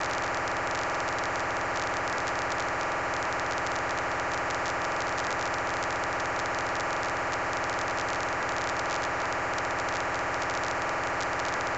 147.150 принят в FM
Постоянная работа на частоте 147,150. Тактовая частота манипуляции 3600.
DMR MOTOTRBO